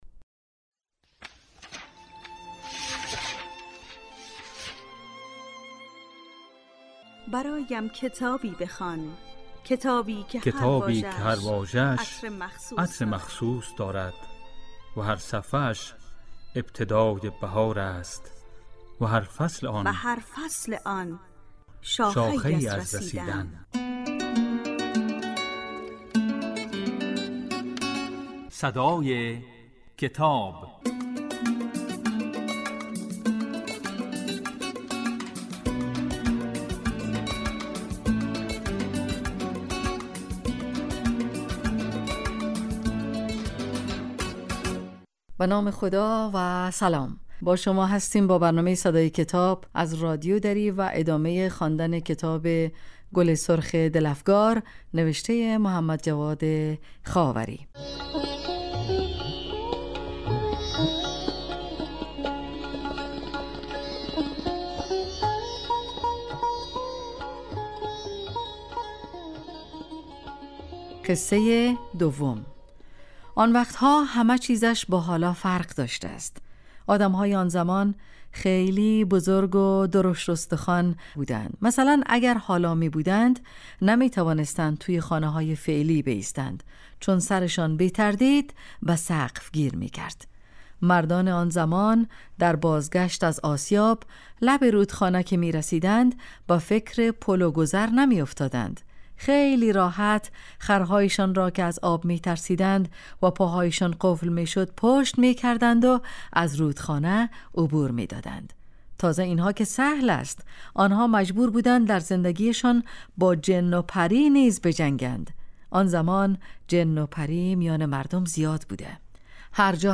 این برنامه کتاب صوتی است و در روزهای یکشنبه، سه شنبه و پنج شنبه در بخش صبحگاهی پخش و در بخش نیمروزی بازپخش می شود.